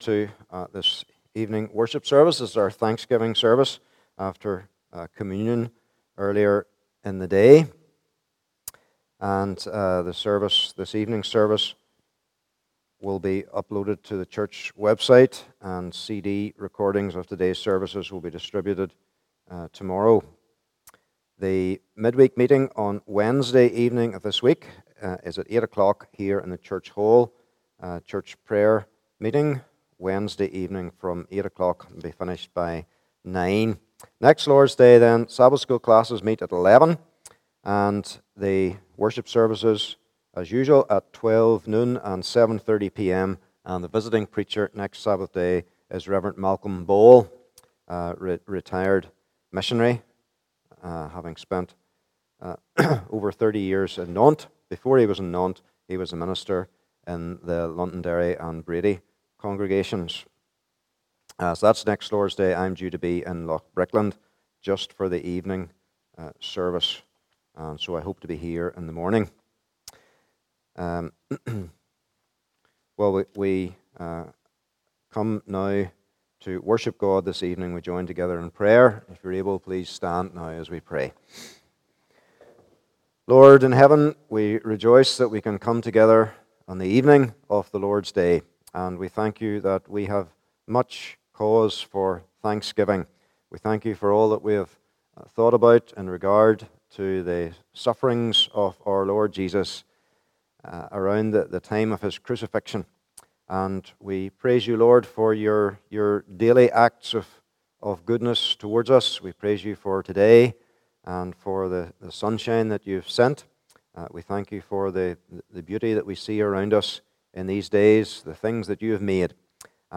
Communion May 2021 Passage: Matthew 27 : 41 - 50 Service Type: Evening Service « Jesus Our Substitute